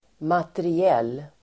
Uttal: [materi'el:]